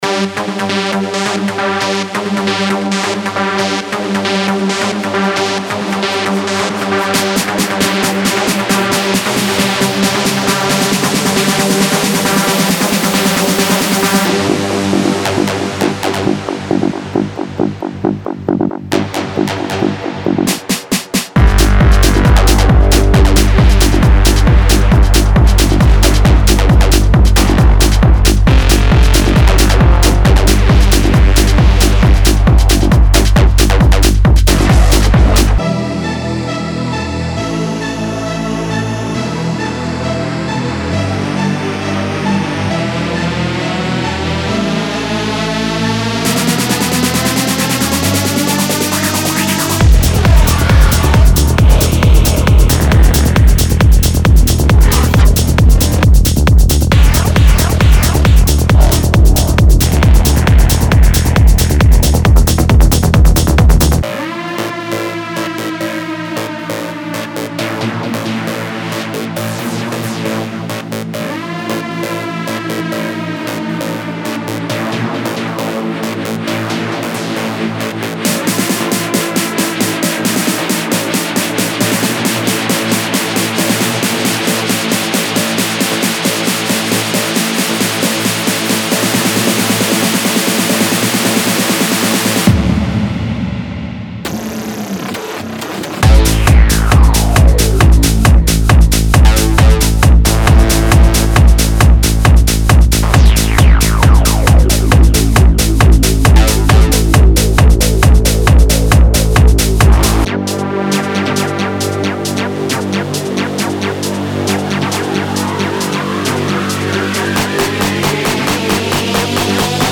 デモサウンドはコチラ↓
Genre:Techno
135 BPM
45 Bass Loops
130 Synth Loops (65 Dry, 65 Wet)
50 Full Drum Loops
12 Noise Risers